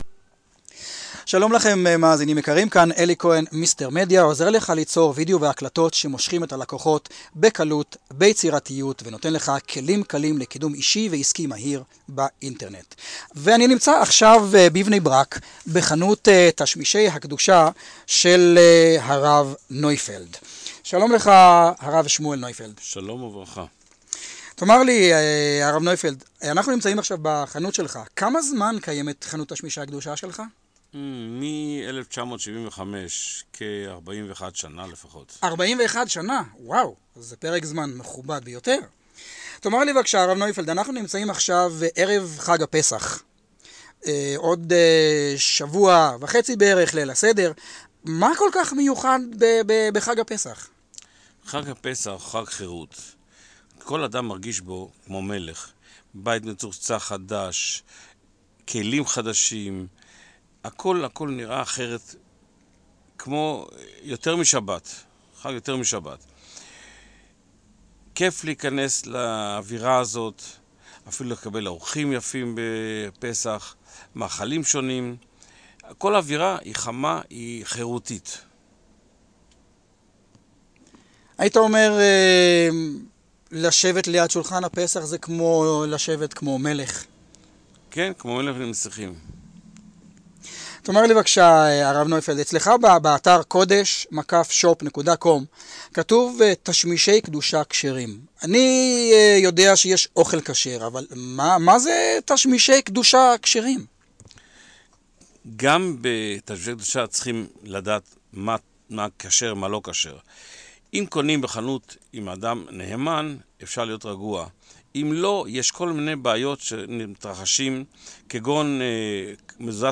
רוב ההקלטות ב-"תחנת הרדיו הפרטית" בוצעו במכשיר הסמארטפון והועלו לכאן ללא כל עריכה, וכך גם אתה יכול להקליט את המסר שלך, ללחוץ עוד קליק או 2, ולשדר את עצמך והמסר שלך - לעולם!